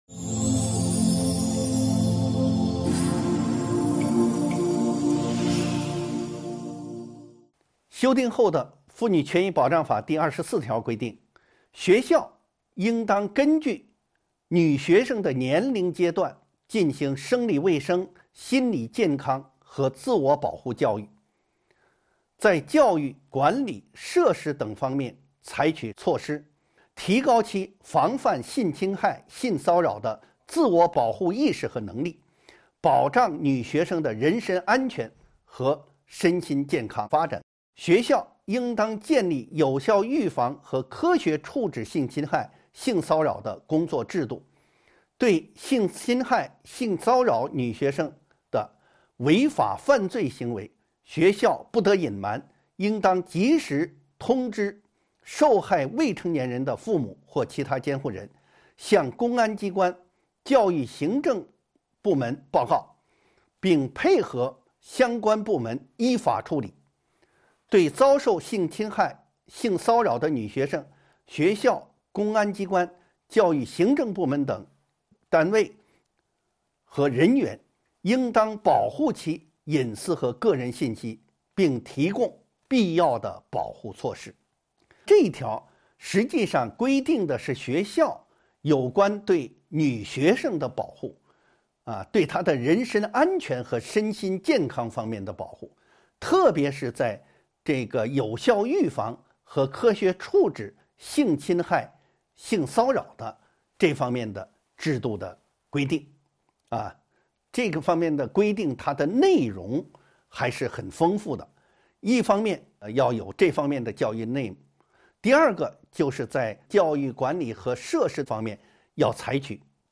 音频微课：《中华人民共和国妇女权益保障法》15.对性侵害的有效预防和科学处置